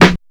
• Clean Acoustic Snare Sound F Key 11.wav
Royality free snare sound tuned to the F note. Loudest frequency: 1171Hz
clean-acoustic-snare-sound-f-key-11-xOc.wav